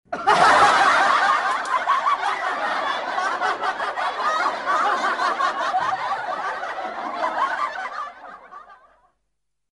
Zil sesi Gülme ses efekti
Kategori Ses Efektleri
Gülme-ses-efekti.mp3